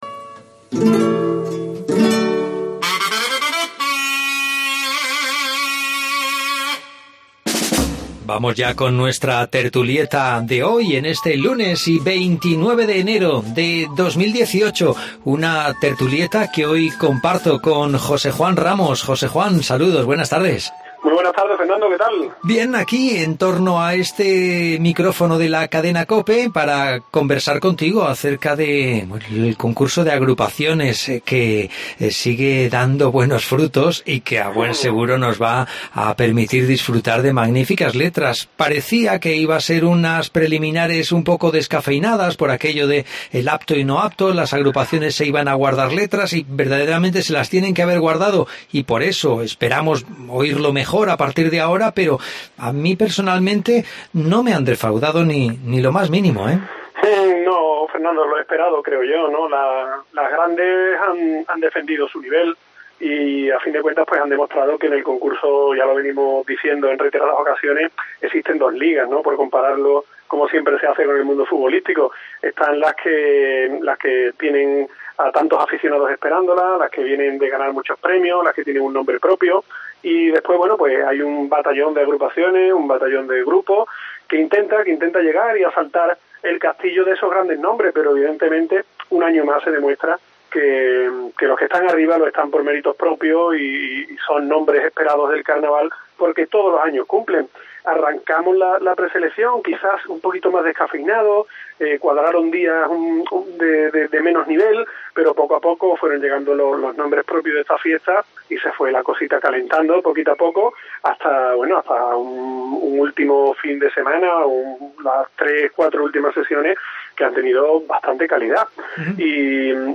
AUDIO: Hablamos del Concurso Oficial de Agrupaciones del Carnaval de Cádiz desde el Gran Teatro Falla, que hoy inicia su fase de Cuartos de Final.